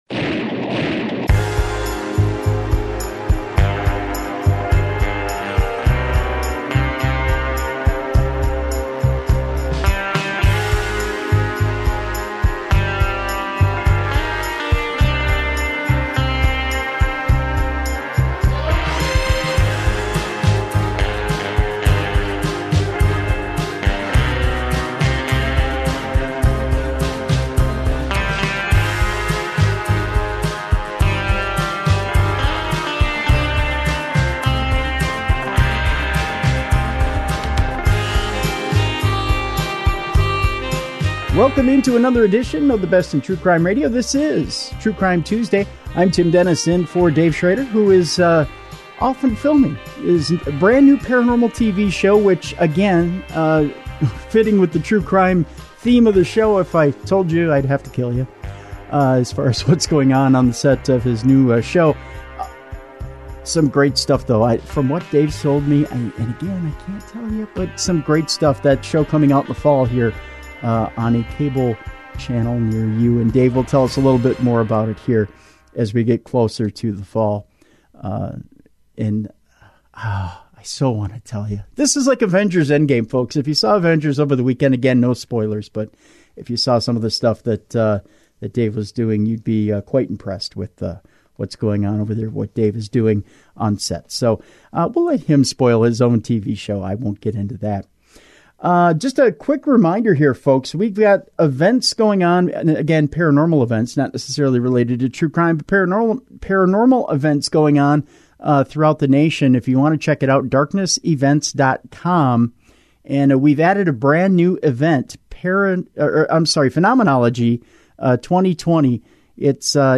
True Crime Tuesday has a sitdown with the: Hollywood Godfather: My Life in the Movies and the Mob Gianni Russo was a handsome 25-year-old mobster with no acting experience when he walked onto the set of The Godfather and entered Hollywood history.